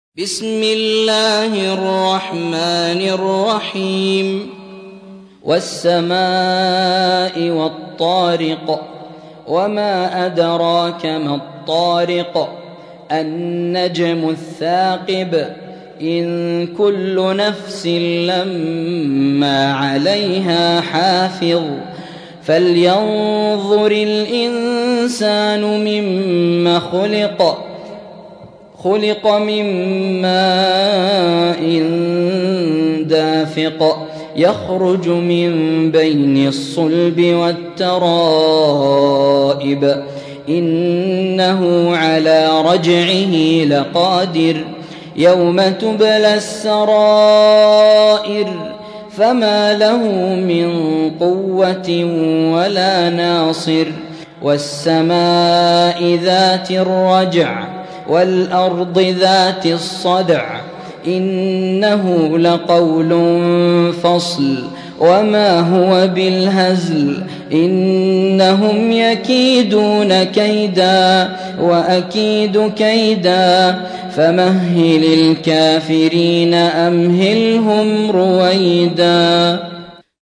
86. سورة الطارق / القارئ